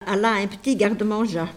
Enquête Arexcpo en Vendée
Catégorie Locution